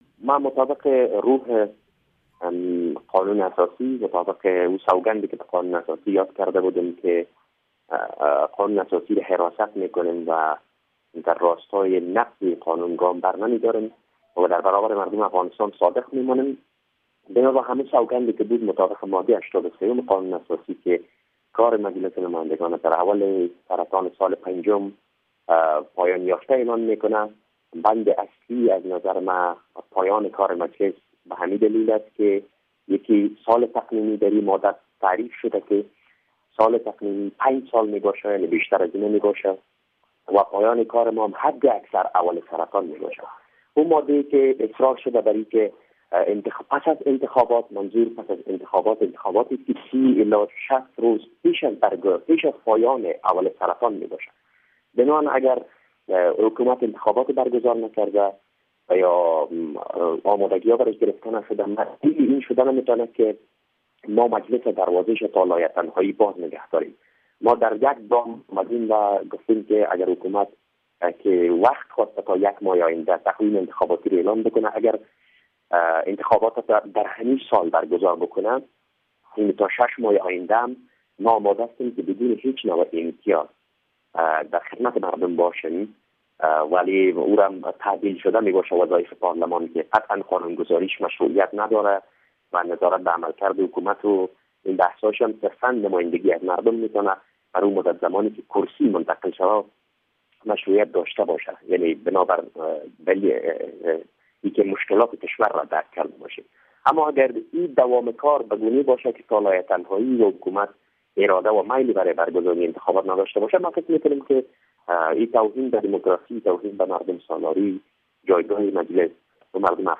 شرح کاملا مصاحبه را در اینجا بشنوید: